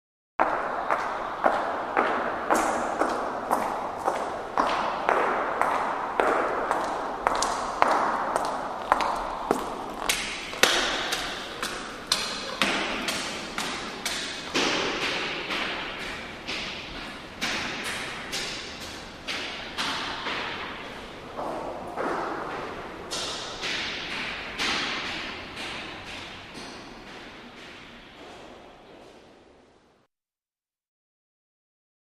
Footsteps By In Echoey Stairwell / Hall 01; Single Person, Hard Heels, Walks Up At Normal Pace, Passes By Then Up Stone Stairs And Away